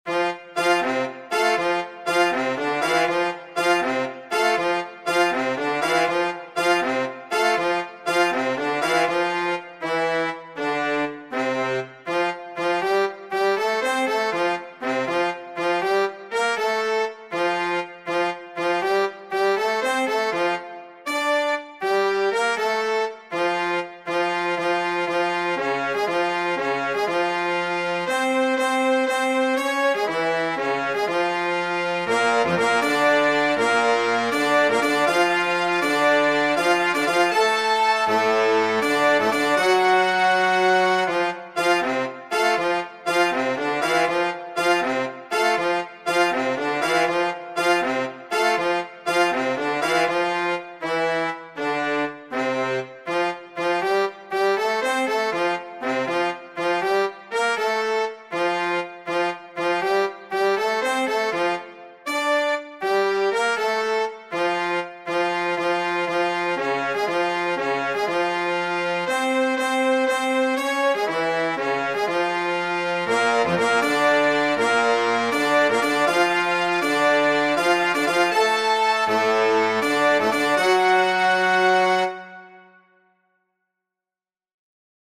Alto_Saxophone
Bb_Trumpet
Horn_in_F
Trombone
Melodica